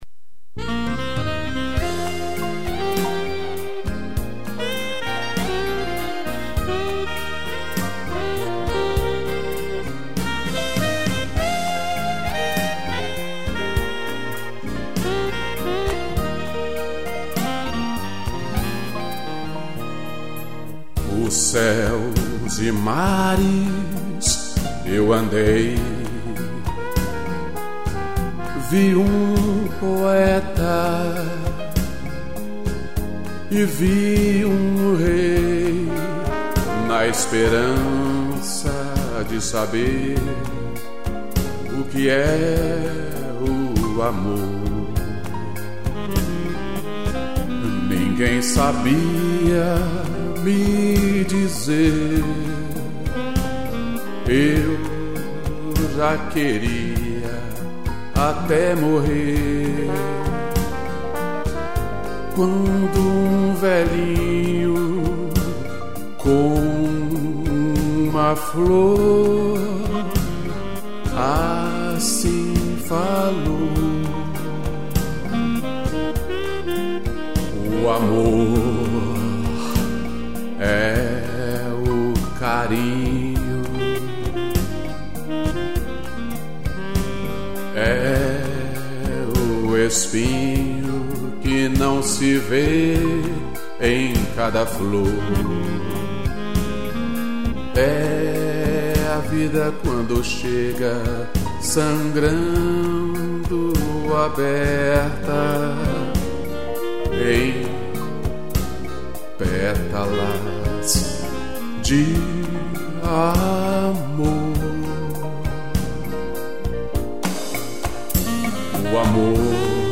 voz e violão
piano, sax e trombone